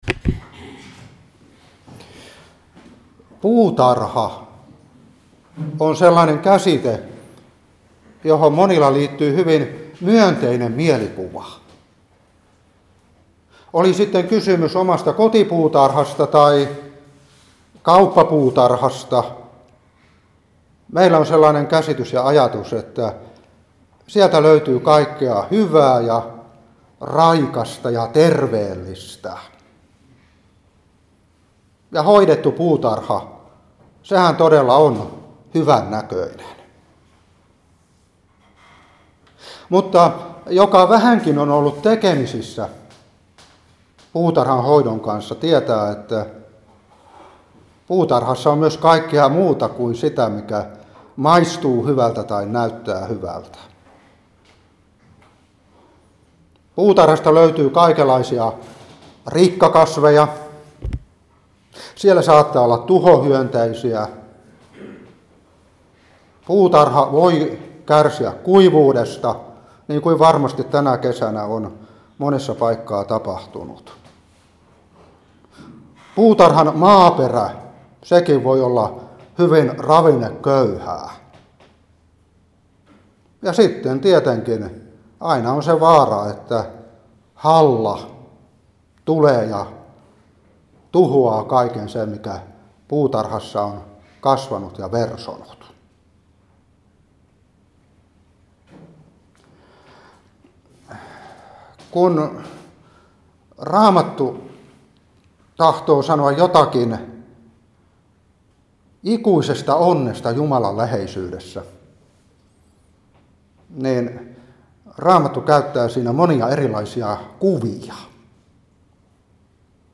Seurapuhe 2021-8. Hes.28:13-17. Ilm.21:18-27.